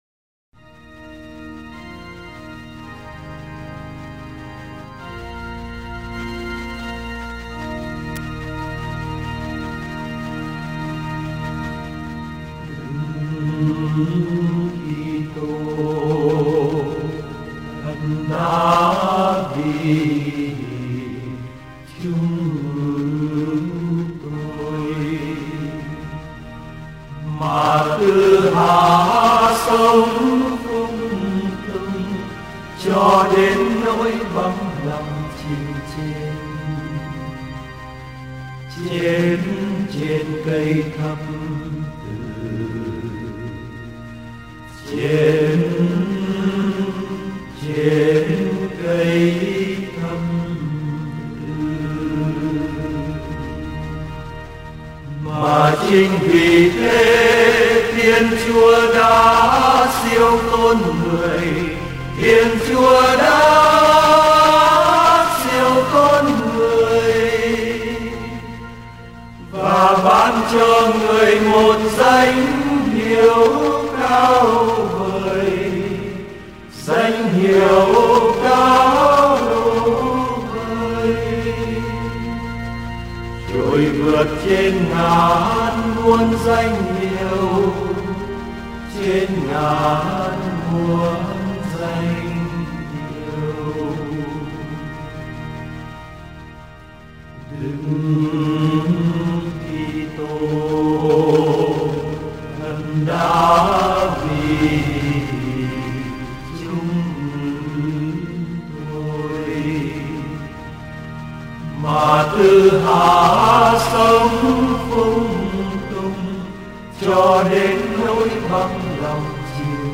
tiếng đàn cũng hay nữa, nghe bài này như là giọng đàn hát trầm trầm mà vang vọng trong các nhà thờ cổ ở Âu châu.